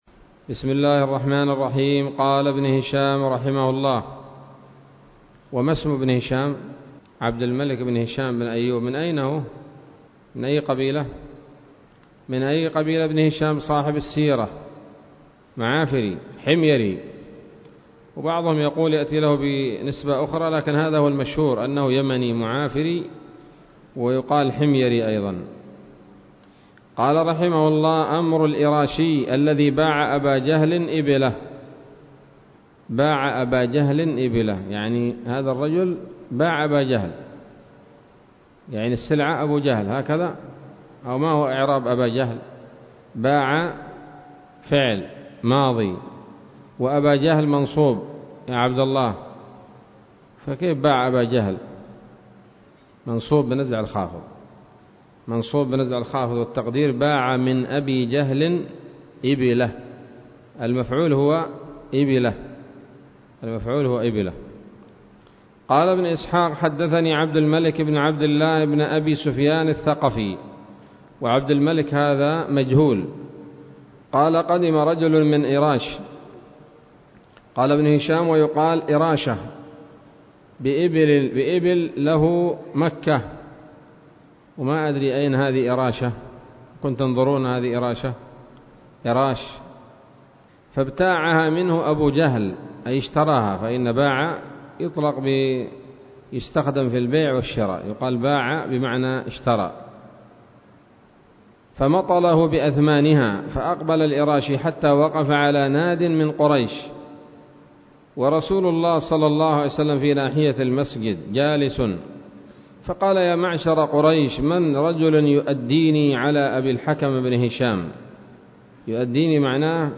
الدرس السادس والأربعون من التعليق على كتاب السيرة النبوية لابن هشام